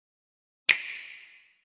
Download Nintendo sound effect for free.